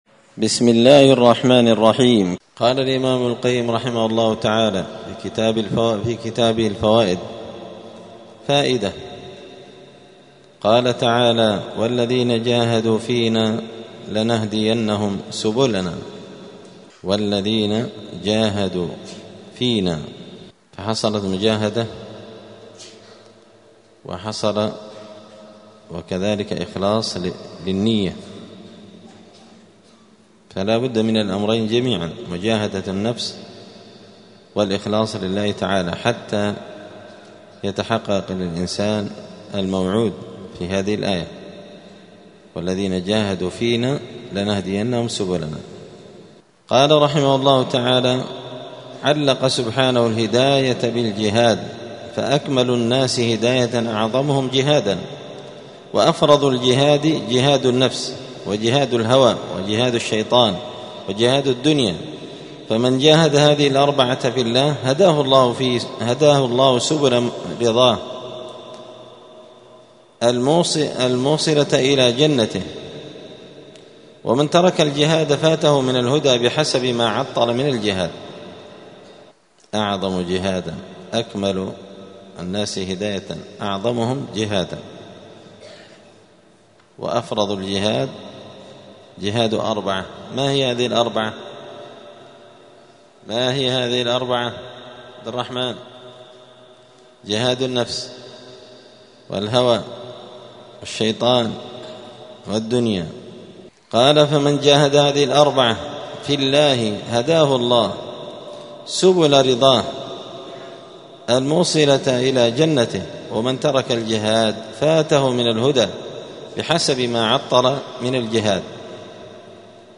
*الدرس الثاني والثلاثون (32) {فصل: أفرض الجهاد جهاد النفس والهوى والشيطان والدنيا}*